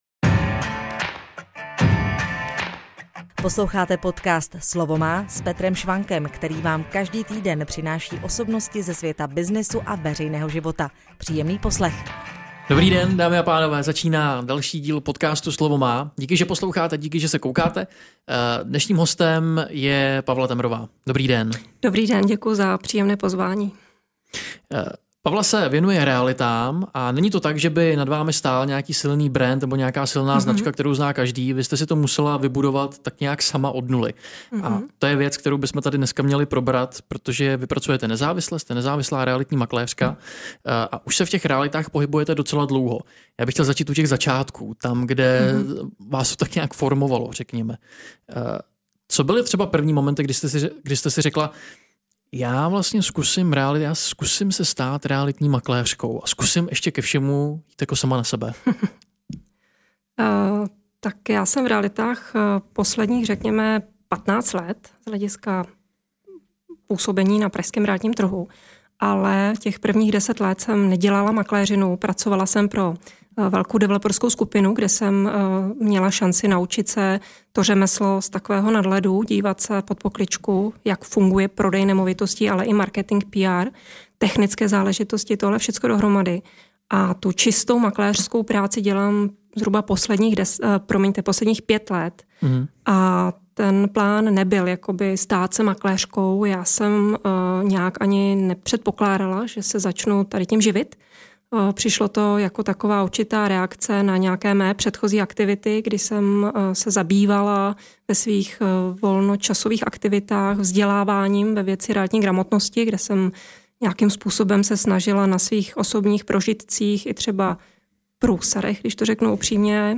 Pokud jsou pro vás aktuální témata jako hypotéka, nájemní bydlení, případně investice do nemovitostí, pak vám bude tento rozhovor užitečný.